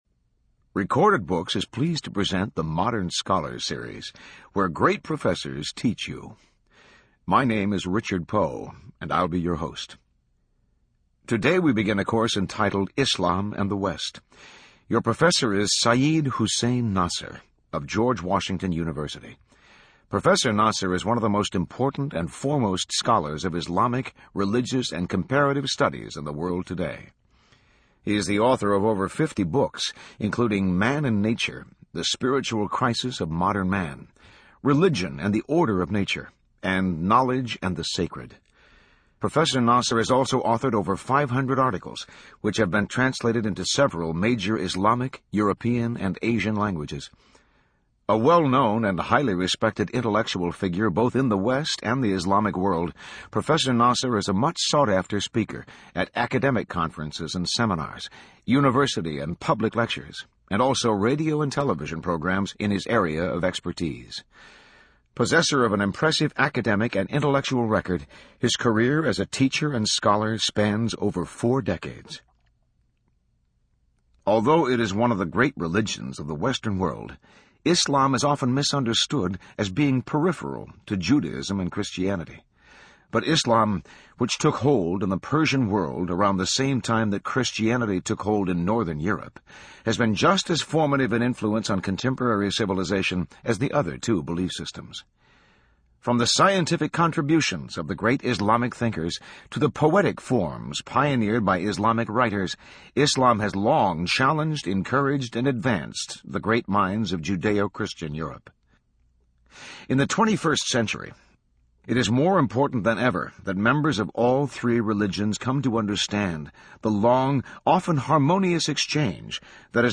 In this lecture professor Seyyed Hossein Nasr discusses the similarities and differences of early western Christendom and the Islamic World known as Dar al-Islam.